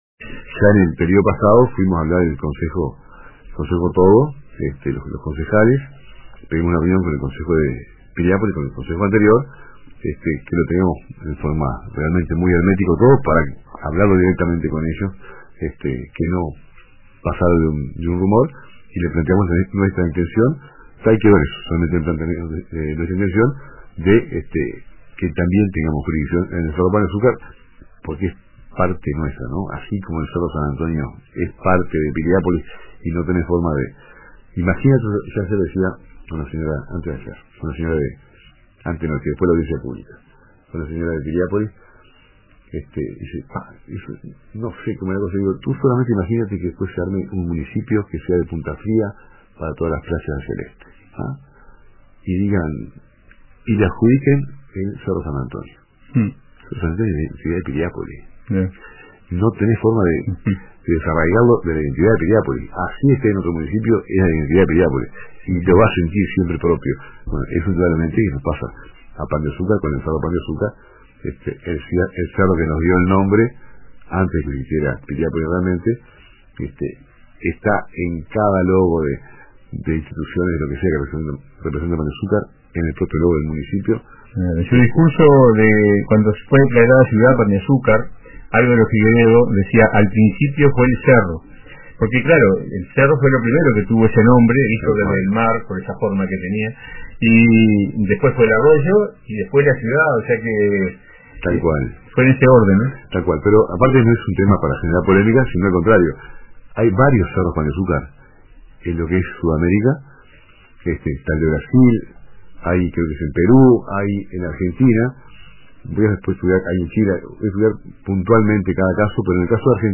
El alcalde del Municipio de Pan de Azúcar, Alejandro Echavarría, participó del programa Radio con Todos de RBC, donde abordó diversos temas vinculados a su gestión.